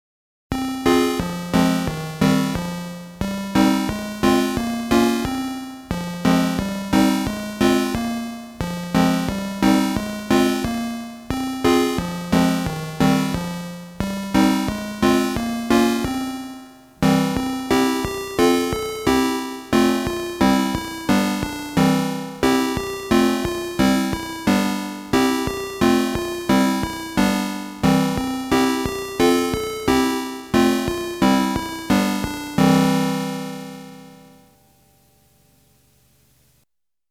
Atari-ST Tunes